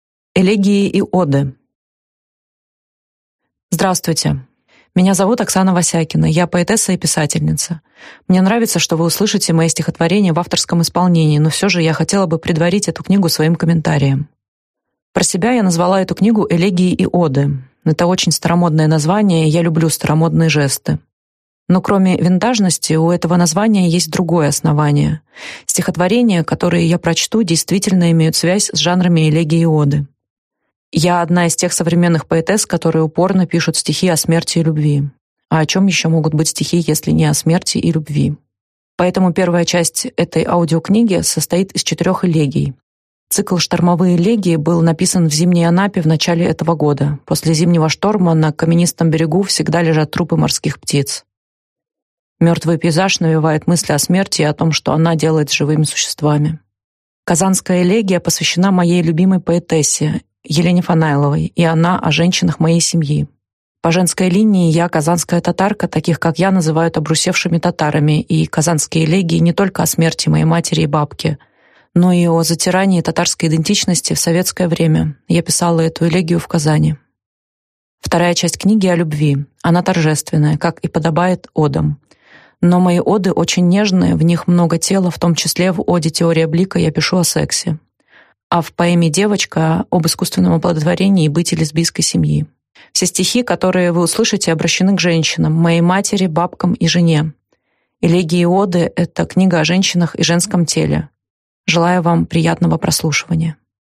Аудиокнига Новая поэзия. Оксана Васякина | Библиотека аудиокниг